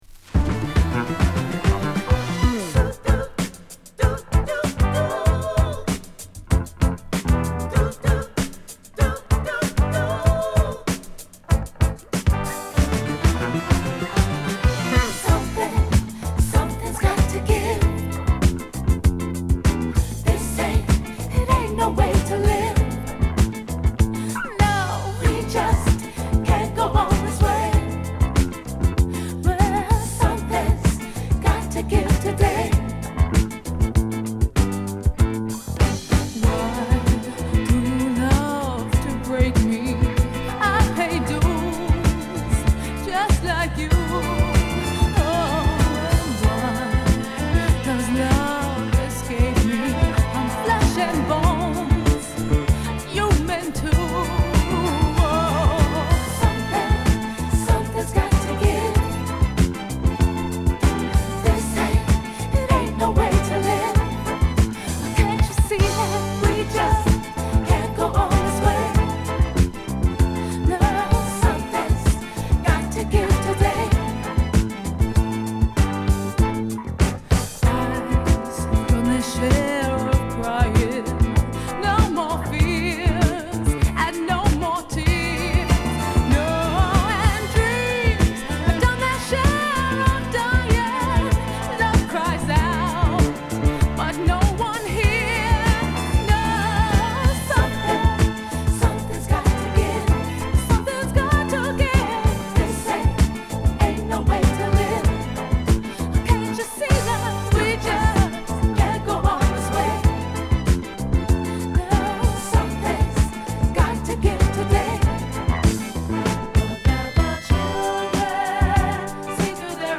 スタジオ・ディスコプロジェクト